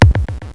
00015_Sound_beat.mp3